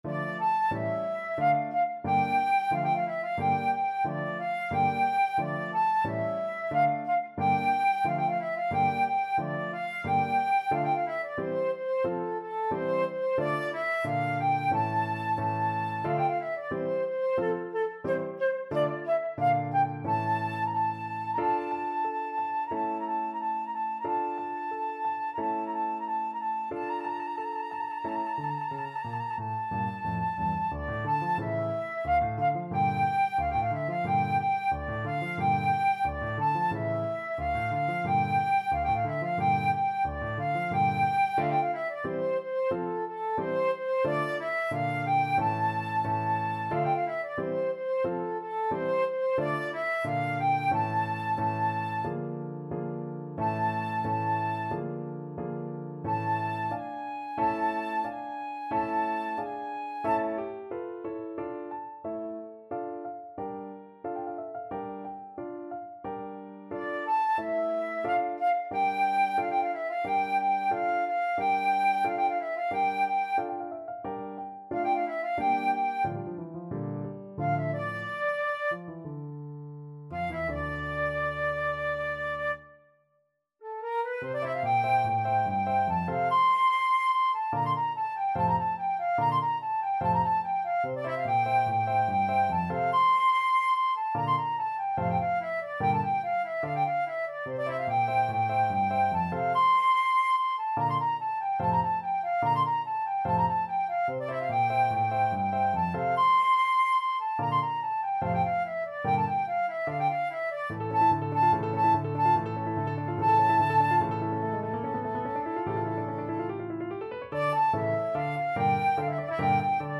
Flute version
FlutePiano
Andantino = 90 (View more music marked Andantino)
2/4 (View more 2/4 Music)
Classical (View more Classical Flute Music)